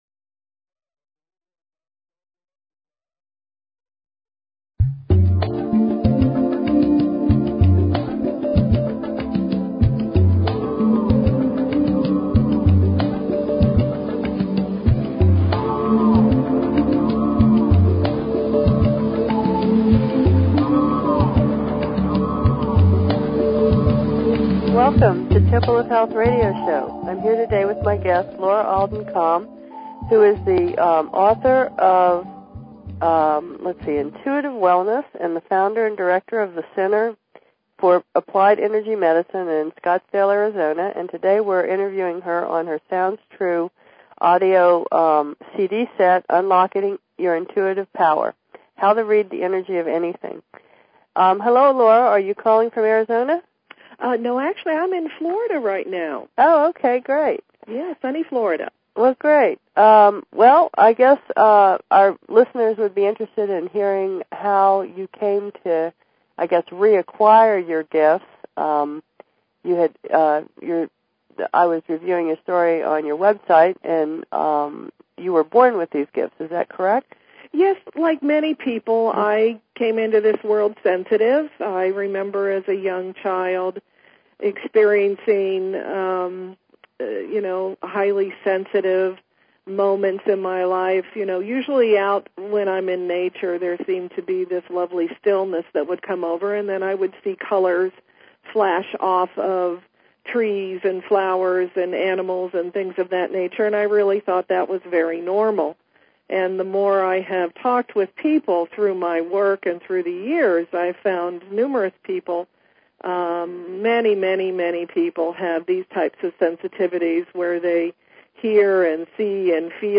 Talk Show Episode, Audio Podcast, Temple_of_Health_Radio_Show and Courtesy of BBS Radio on , show guests , about , categorized as